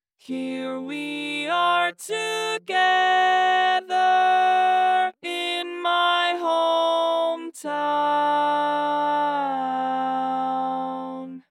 Key written in: F Major
Type: Female Barbershop (incl. SAI, HI, etc)